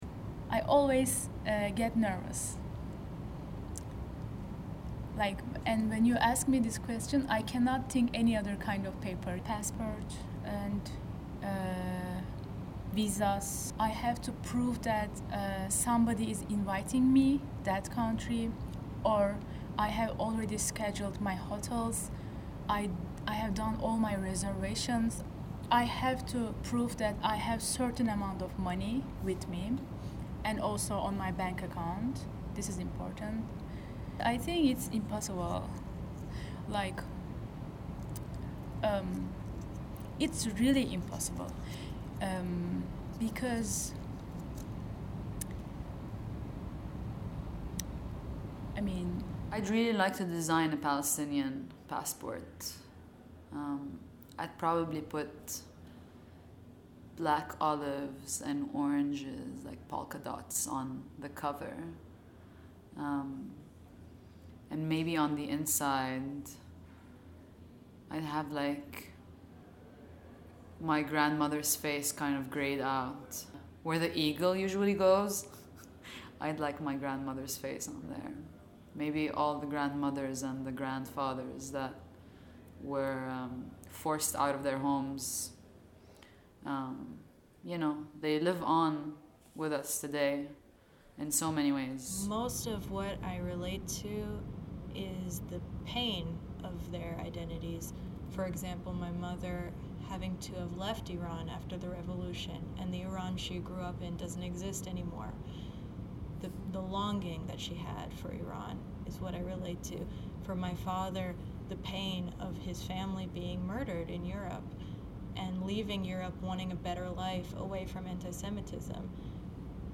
Walk through a guided audio tour and hear the experiences of people identifying themselves beyond their papers.
showmeyourpapers_conversation.mp3